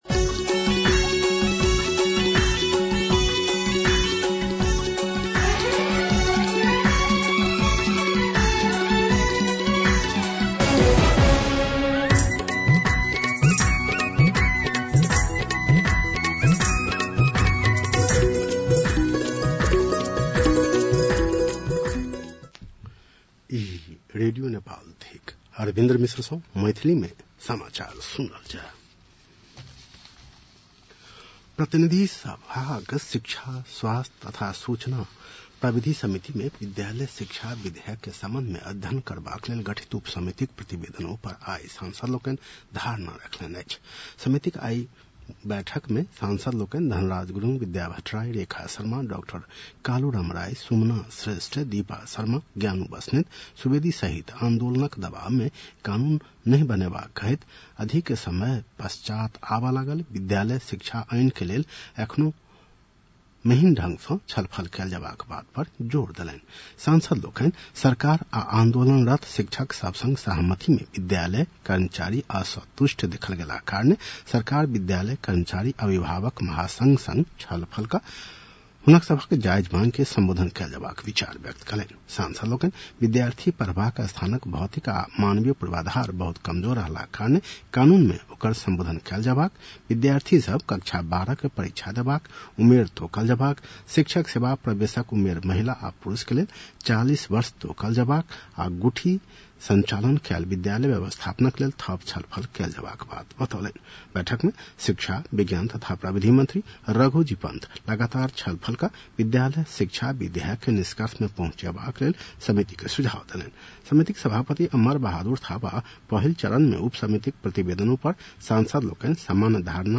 मैथिली भाषामा समाचार : ७ जेठ , २०८२